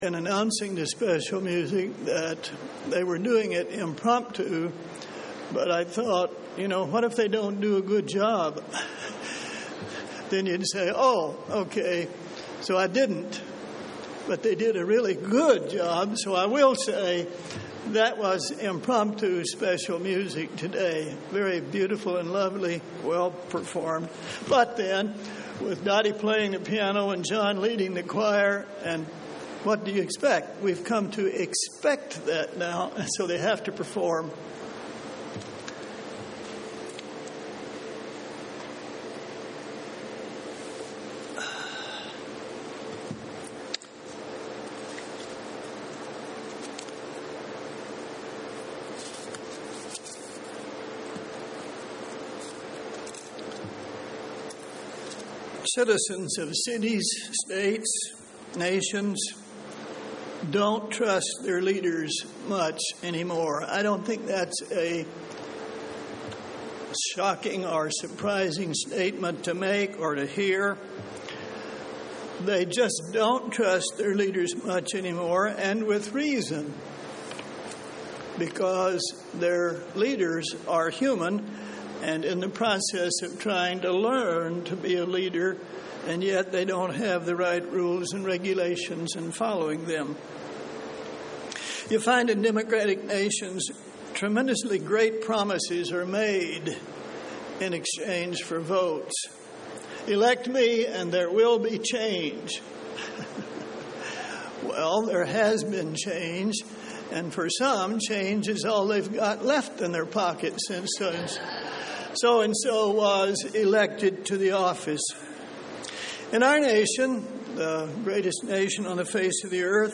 Given in Columbus, OH
UCG Sermon Studying the bible?